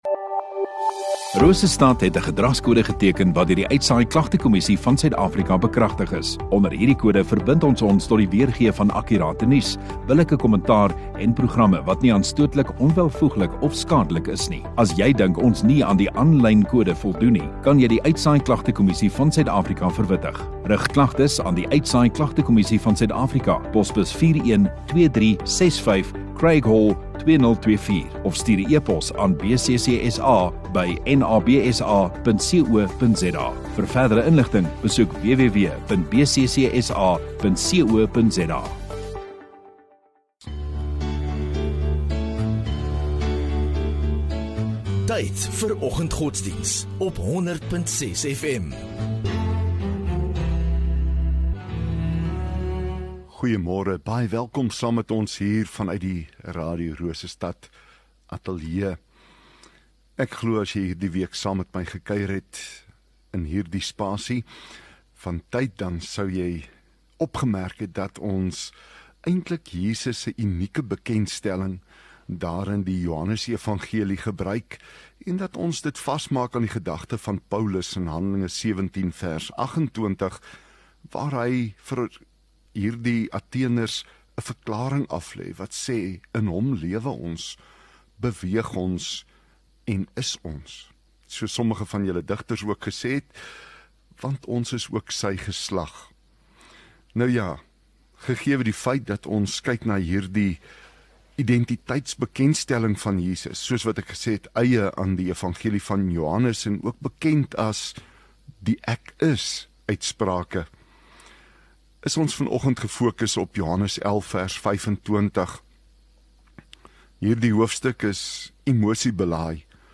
21 Aug Donderdag Oggenddiens